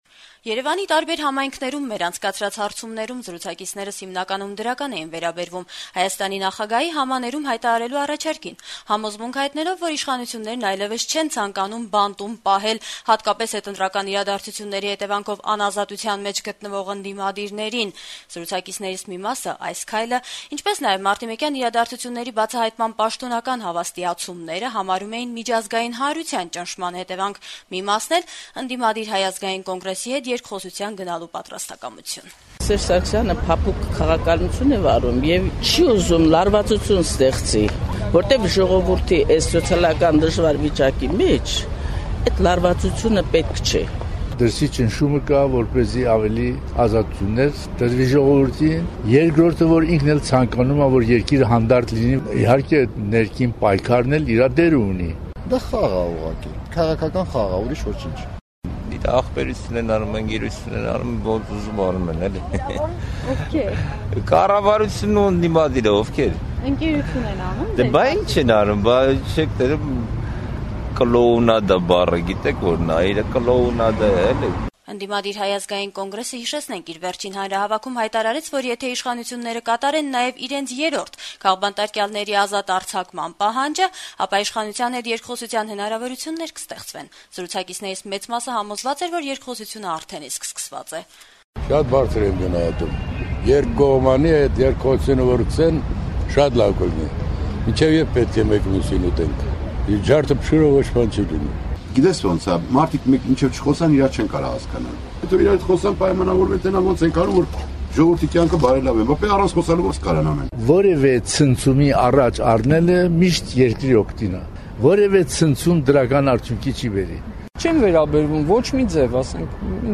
«Ազատություն» ռադիոկայանի հետ զրույցում երեւանցիները հիմնականում դրական էին արտահայտվում Հայաստանի նախագահի՝ համաներում հայտարարելու առաջարկին, համոզմունք հայտնելով,որ իշխանություններն այլեւս չեն ցանկանում բանտում պահել հատկապես հետընտրական իրադարձությունների հետեւանքով անազատության մեջ գտնվող ընդդիմադիրներին: